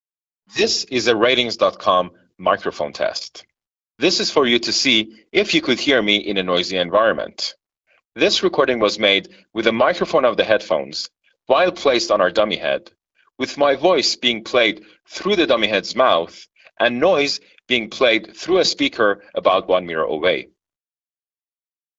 While the Apple AirPods Pro 3 aren't specifically designed for use with Android devices, their mic system captures fuller and clearer, albeit slightly sibilant,
audio when paired with a Samsung Galaxy Flip 7 compared to our rig.